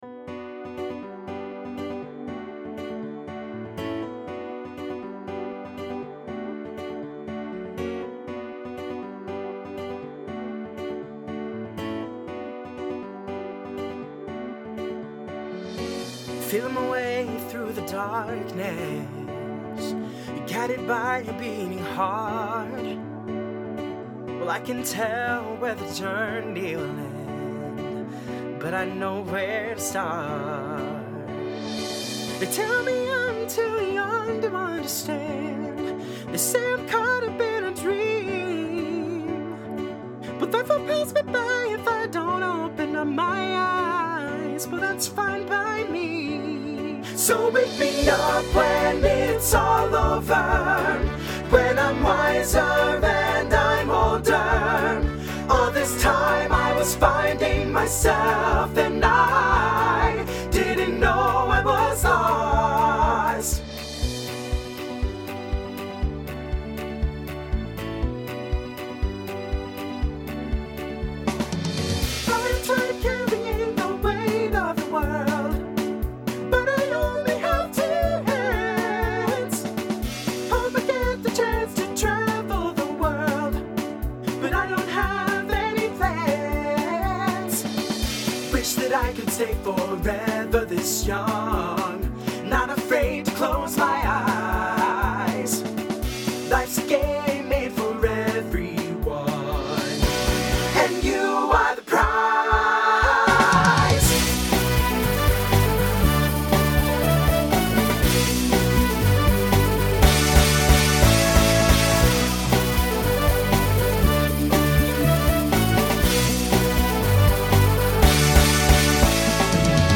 Voicing SATB Instrumental combo Genre Folk , Rock